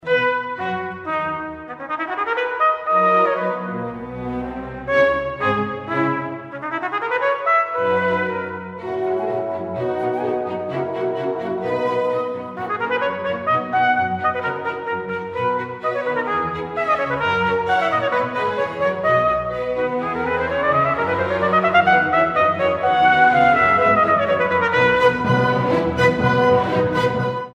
E natural trumpet